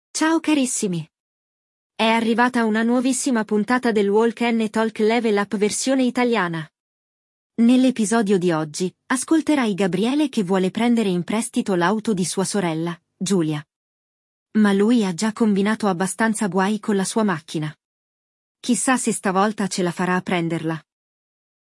IL DIALOGO